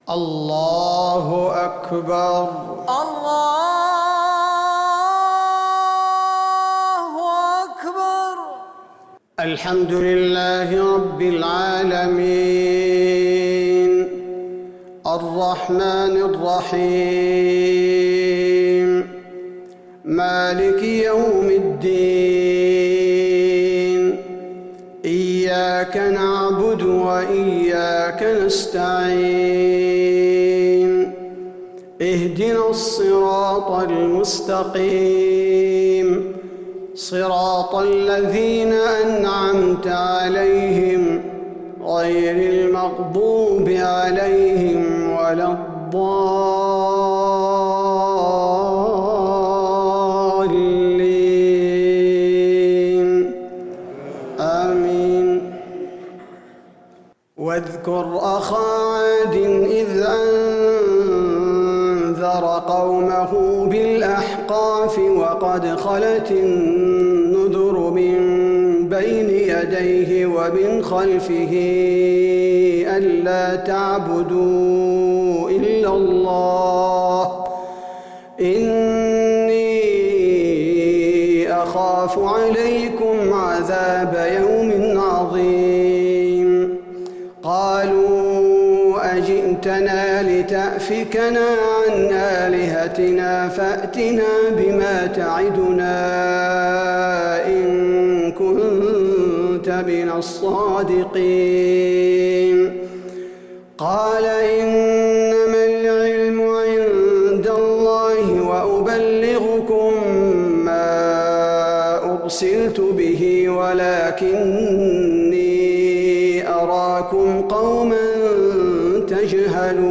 صلاة العشاء للقارئ عبدالباري الثبيتي 3 ربيع الأول 1442 هـ
تِلَاوَات الْحَرَمَيْن .